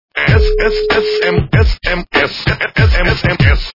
При прослушивании Звонок для СМС - С-С-С-С-М-С-С-М-С качество понижено и присутствуют гудки.
Звук Звонок для СМС - С-С-С-С-М-С-С-М-С